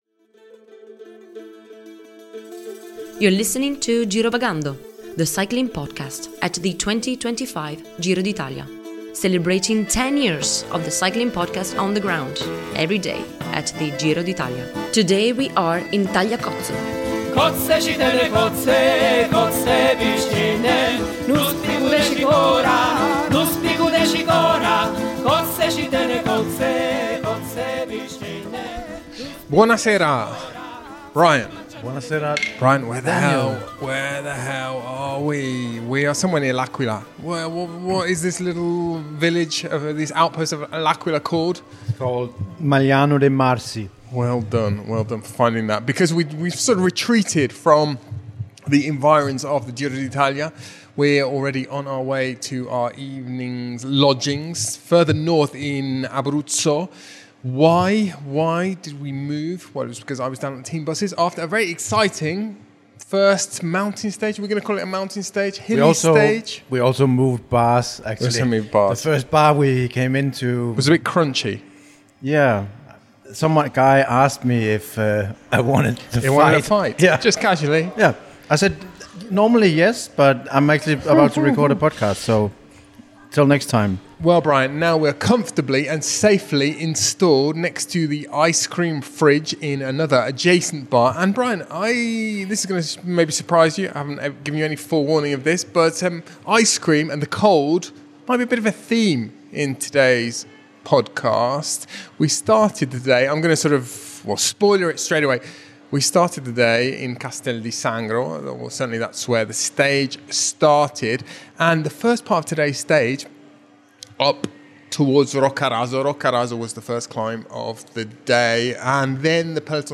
Join us for daily coverage of the Giro d’Italia recorded on the road as the race makes its way from Albania to Rome.